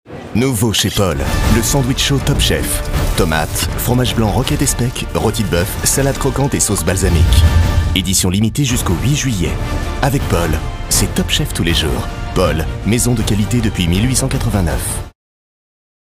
Voix-off souriante et dynamique pour les boulangeries Paul et leur sandwich Top Chef
Dynamique, gourmand et souriant.
Pub tv pour les boulangeries Paul et son nouveau sandwich chaud Top Chef.
Une expérience gourmande qui a nécessité une interprétation vocale à la fois dynamique, naturelle et souriante, afin de refléter l’enthousiasme et la convivialité de la marque.
Pour ce projet, j’ai adapté ma voix à une tonalité médium grave, apportant une certaine chaleur à mon interprétation.